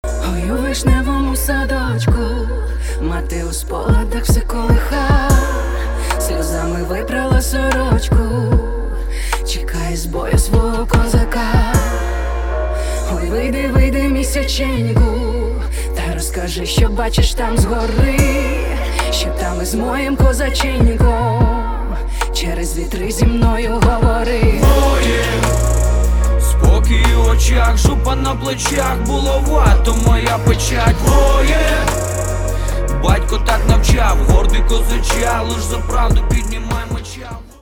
• Качество: 256, Stereo
мужской вокал
брутальные
Rap
красивый женский голос
фолк
воодушевляющие
эпичные